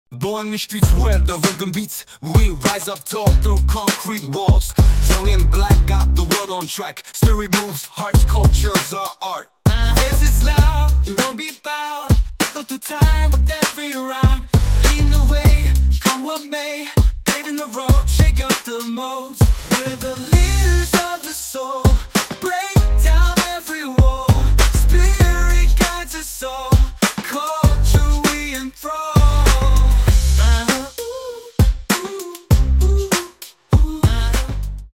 Short version of the song, full version after purchase.
An incredible Hip Hop song, creative and inspiring.